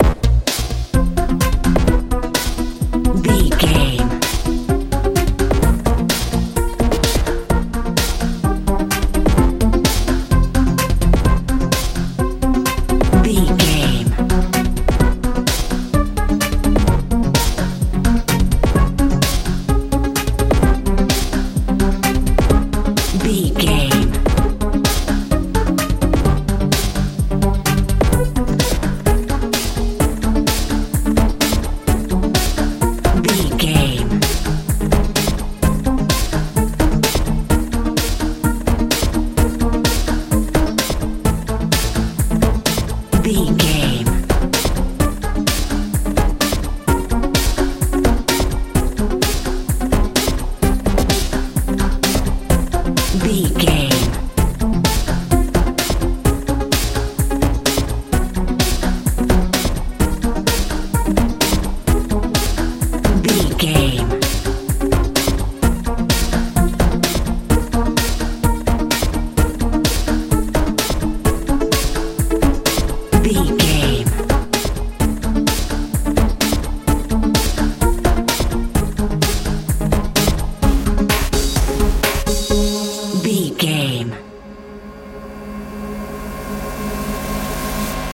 pop dance feel
Ionian/Major
strange
playful
bass guitar
drums
synthesiser
80s
suspense